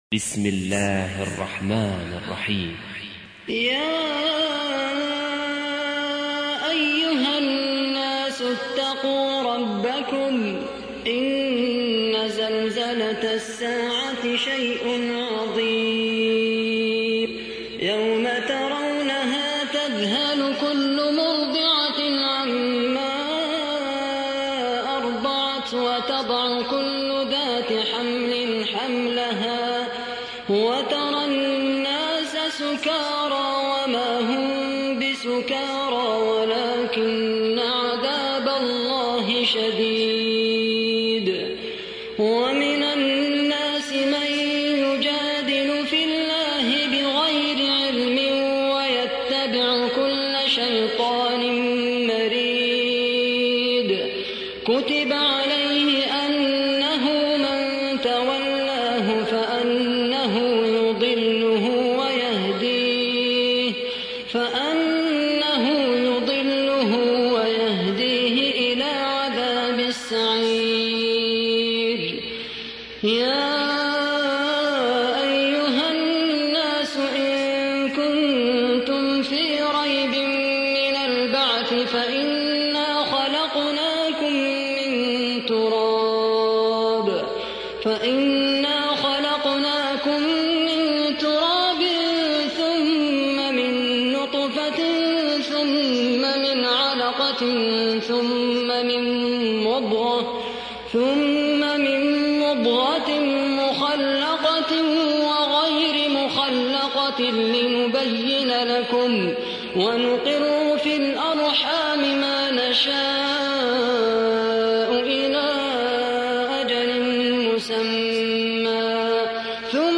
تحميل : 22. سورة الحج / القارئ خالد القحطاني / القرآن الكريم / موقع يا حسين